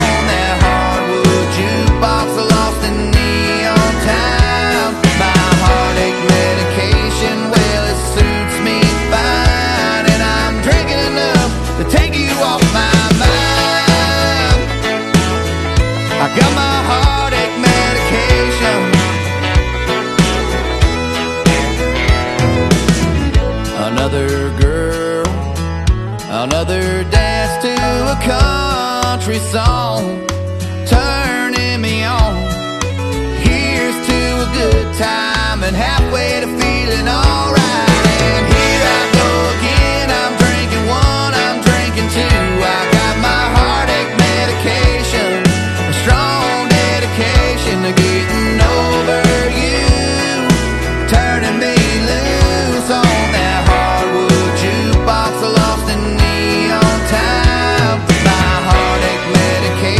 Check out this Chevy Silverado sound effects free download